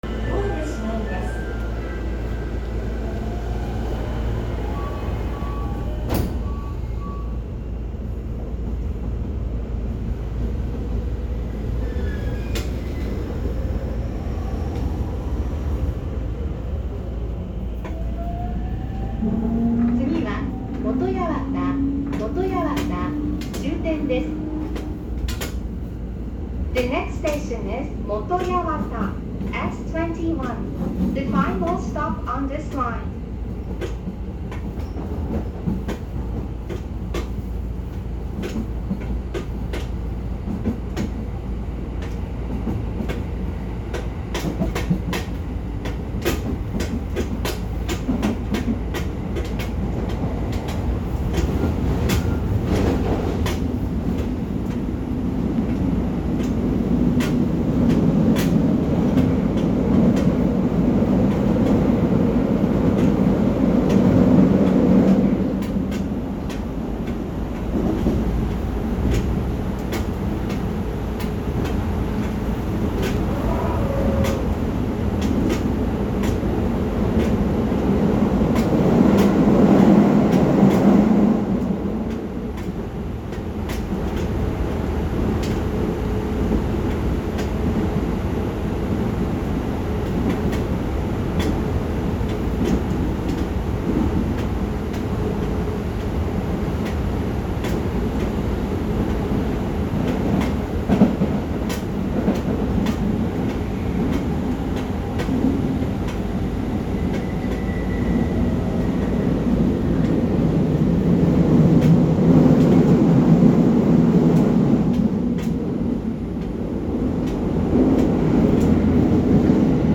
・10-300形（2次車まで）走行音
【新宿線】大島→船堀
線路の幅を都営新宿線に合わせただけで、基本的にJR東日本のE231系(500番台etc)と全く同じ三菱IGBTです。ドアチャイムもJRタイプですが、若干音程が低くなっています。